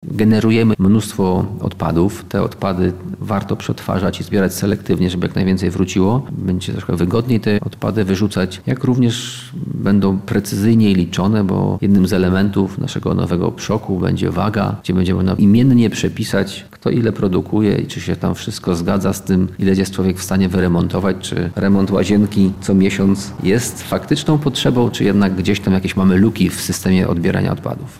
- Zmiana będzie wizerunkowa i jakościowa - zapowiada Mariusz Majkutewicz, burmistrz